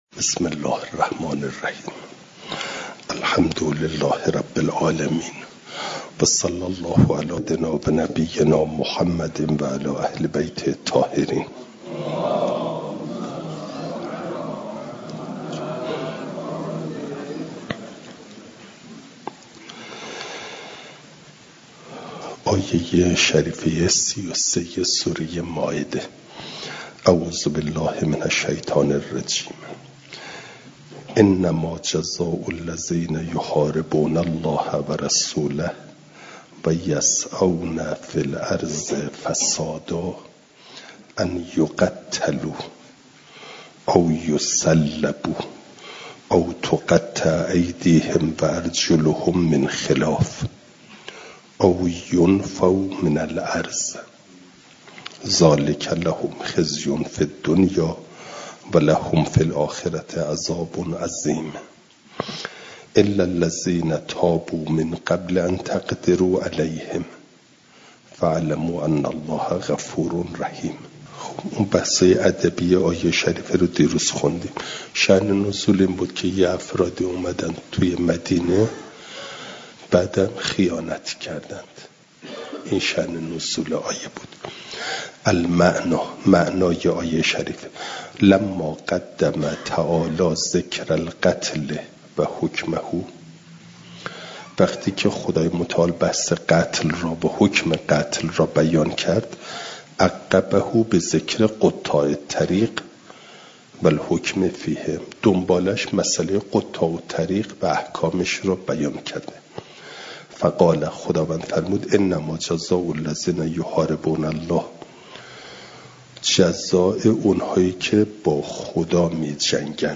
جلسه چهارصد و سی و دوم درس تفسیر مجمع البیان